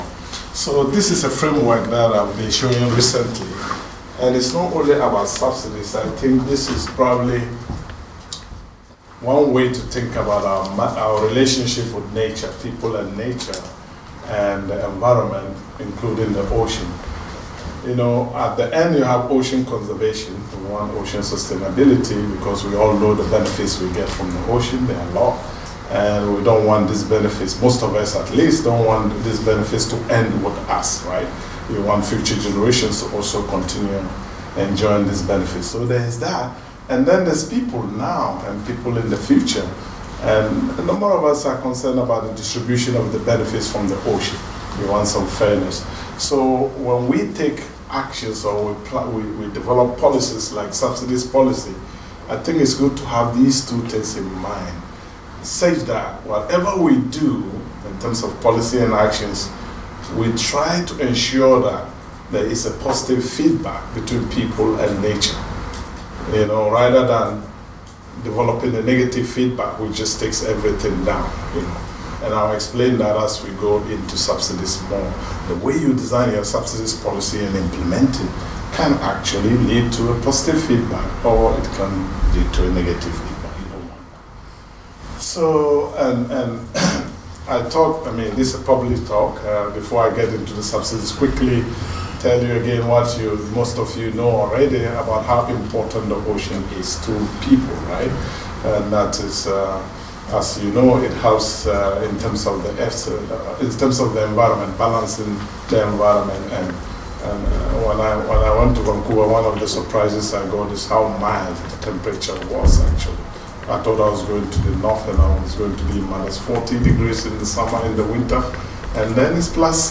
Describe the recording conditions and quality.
A lively question-and-answer session followed, and you can watch the entire event on Facebook Live in the video below.